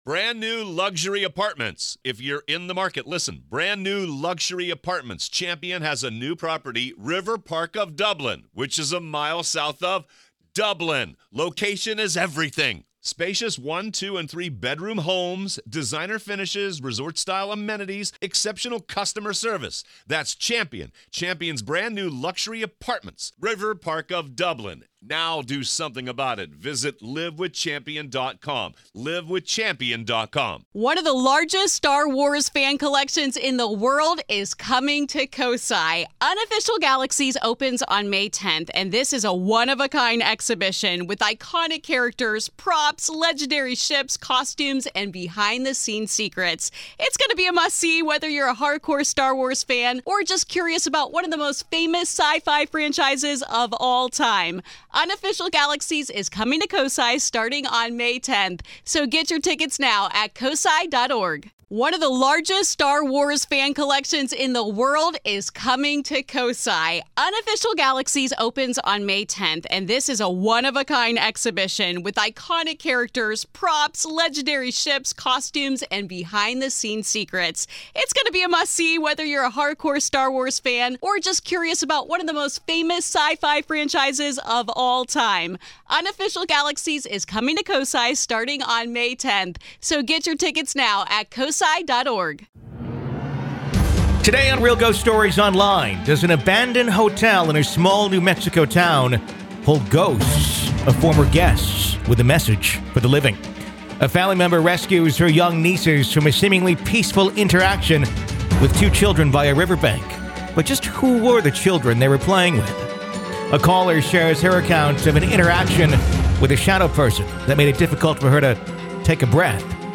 A caller shares her account of an interaction with a shadow person that made it difficult for her to breath.